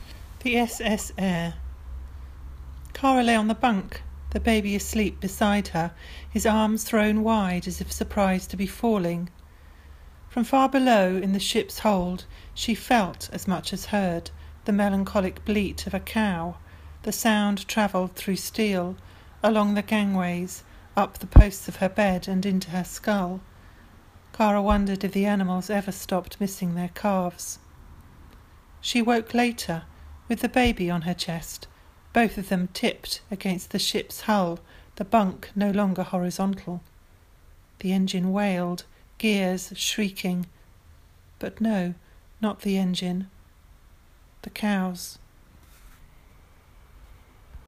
This week I thought it might be fun to also post a recording of me reading my story.